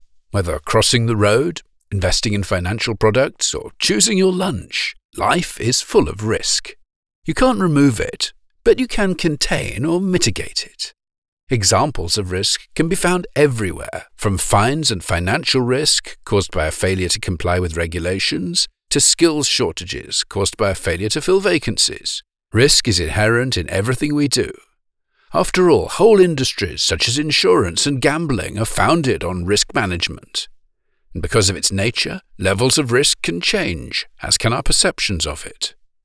British E-Learning Narrator:
Thanks to this background, I am extremely capable of delivering e-learning voiceovers in an appropriately lively, friendly and engaging manner.